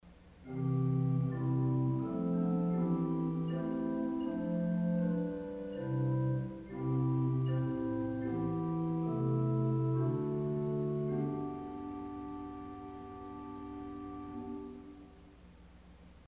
The sound clips were recorded using a Schoeps MK 21 microphone feeding a Zoom H4 digital recorder, recording directly to 160 bit mp3 format.
8' Cor de Nuit   Keller 1968-72 arpeggio
CordeNuitStAnne.mp3